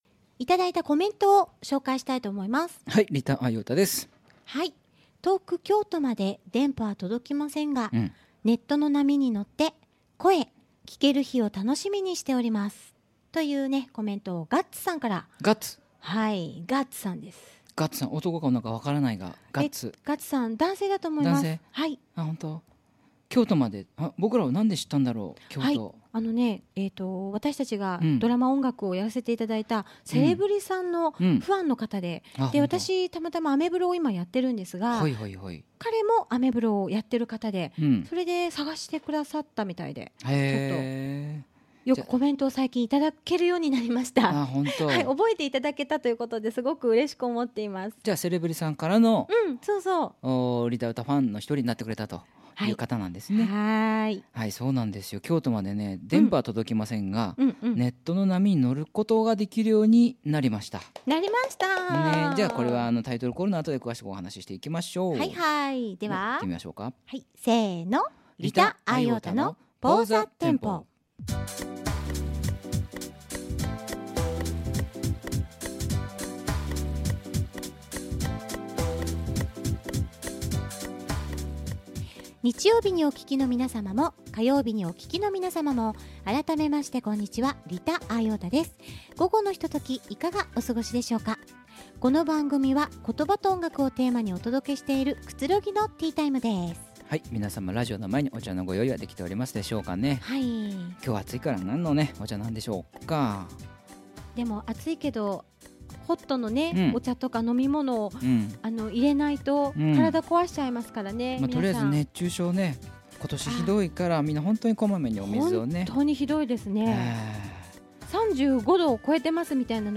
オリジナル朗読